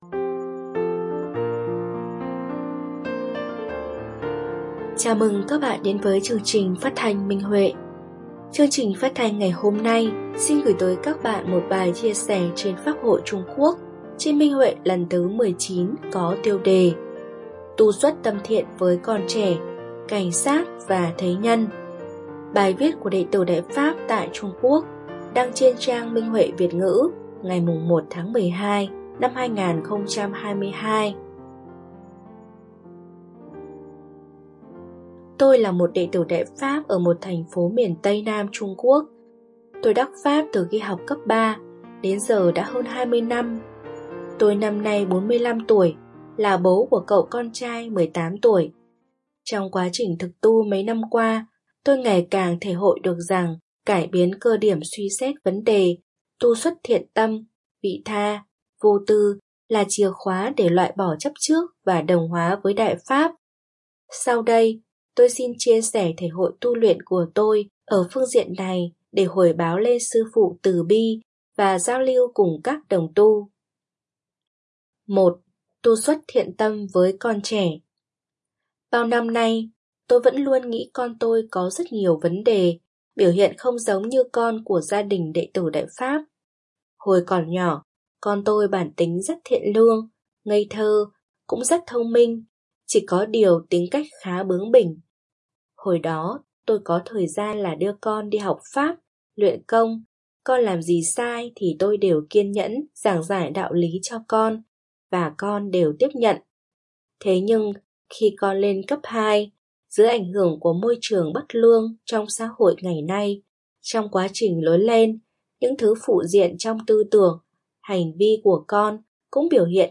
Chương trình phát thanh số 4: Bài viết chia sẻ tâm đắc thể hội trên Pháp hội Trung Quốc lần thứ 19 có tiêu đề Tu xuất tâm thiện với con trẻ, cảnh sát và thế nhân, bài viết của đệ tử Đại Pháp tại Trung Quốc Đại Lục.